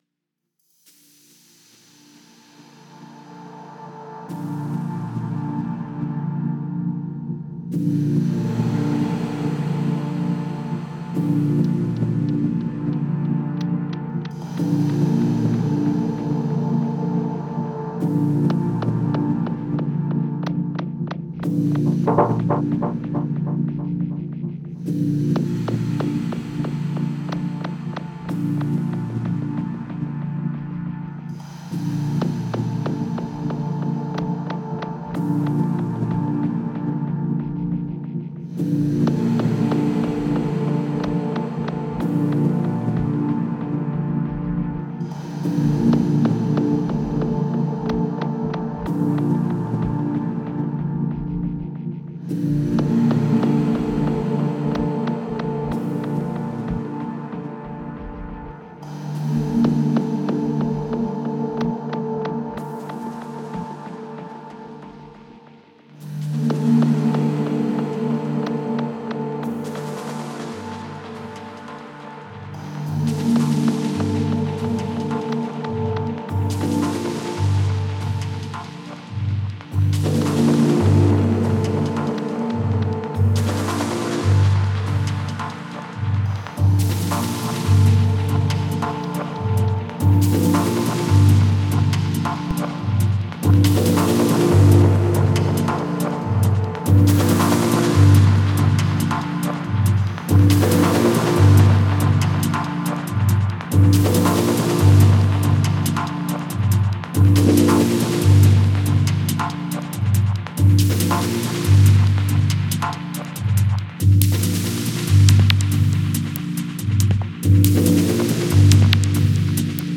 electronic music releases
Катэгорыі: my, snd, techno, dub.